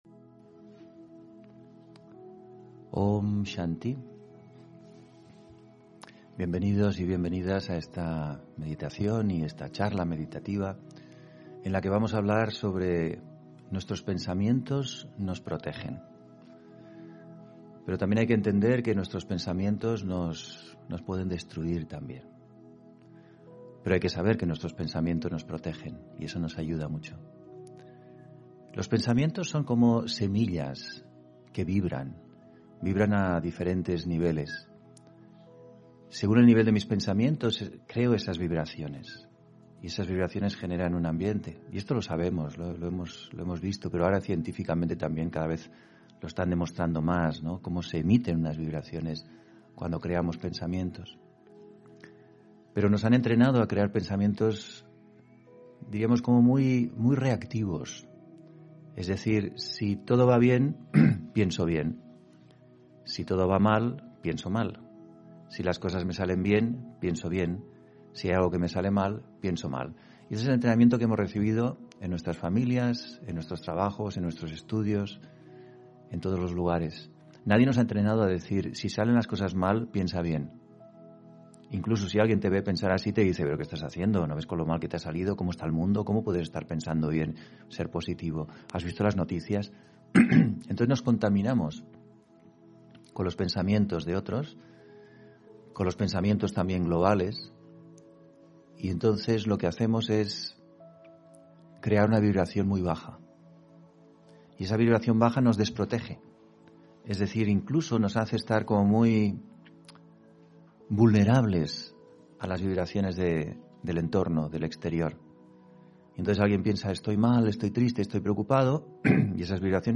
Audio conferencias
Meditación y conferencia: Tus pensamientos te protegen (25 Febrero 2022)